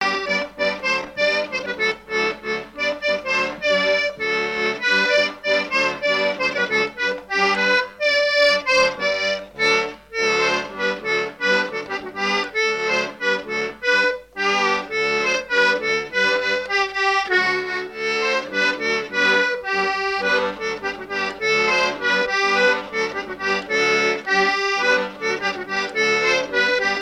Falleron ( Plus d'informations sur Wikipedia ) Vendée
Divertissements d'adultes - Couplets à danser
branle : courante, maraîchine
Pièce musicale inédite